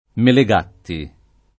[ mele g# tti ]